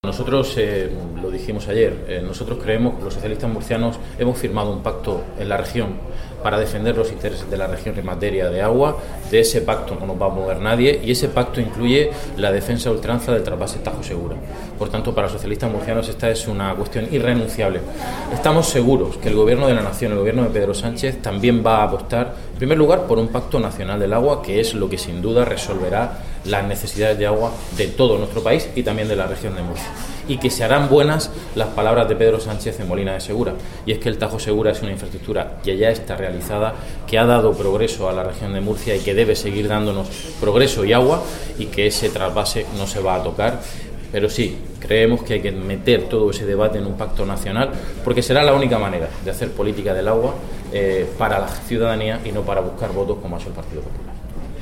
CORTE_DE_VOZ_DE_JOAQUIN_LOPEZ_PAGAN-_TRASVASE_TTS.mp3